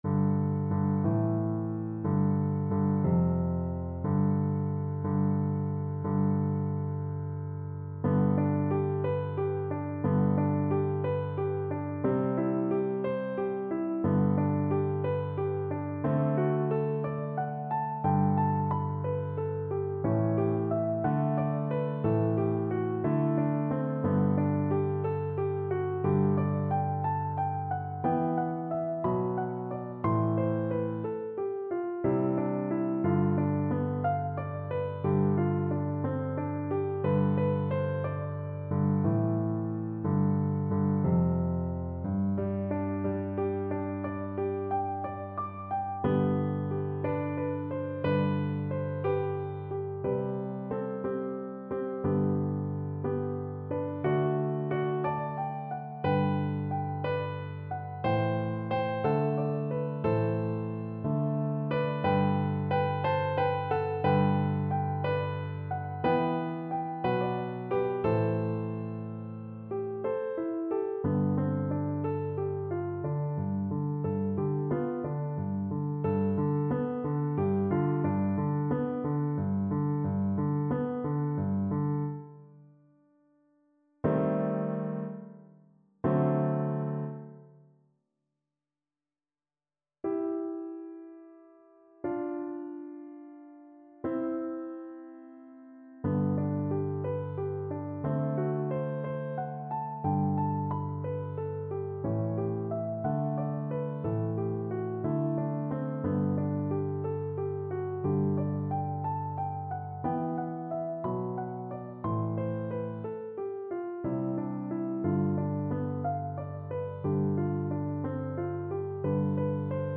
Cello
Traditional Music of unknown author.
G major (Sounding Pitch) (View more G major Music for Cello )
6/8 (View more 6/8 Music)
Andante .=c.60
G3-C5